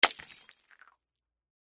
Звук разбивающейся об землю бутылки шампанского